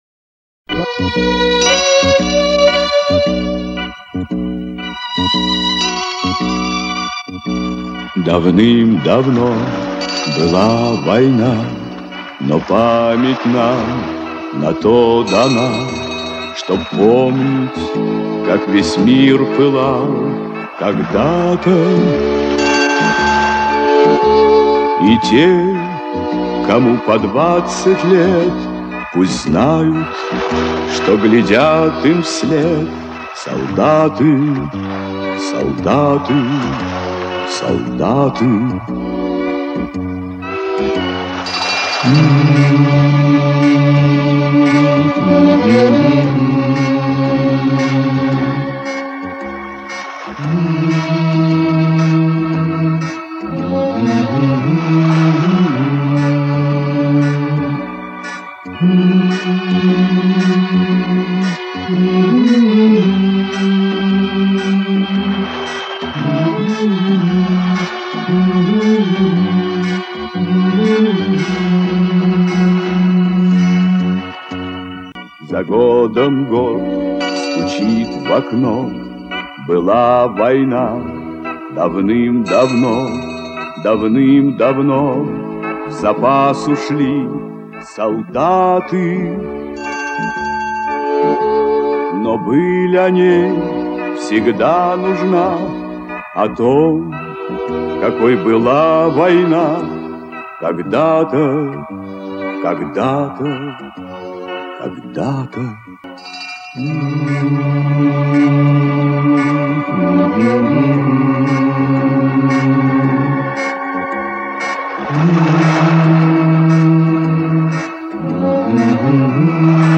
Обратите внимание -  в фильме песню исполняют два вокалиста.
Вот саундтрек из фильма (судя по фону).